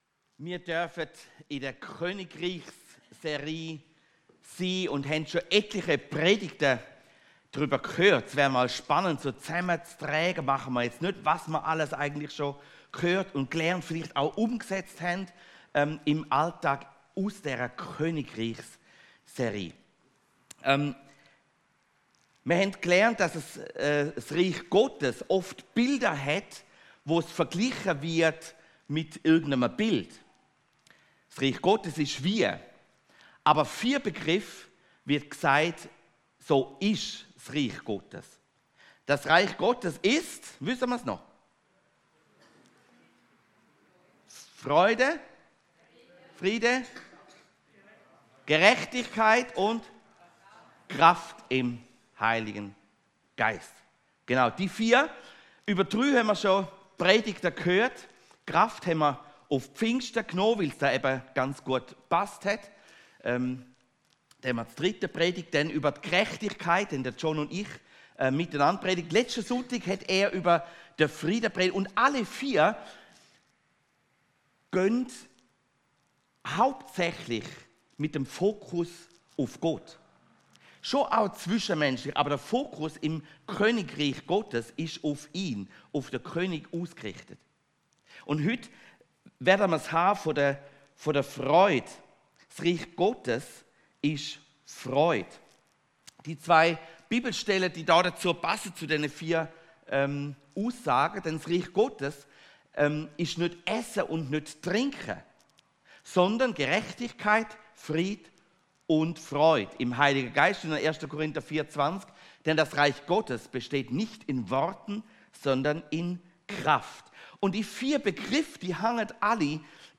Hier hörst du die Predigten aus unserer Gemeinde.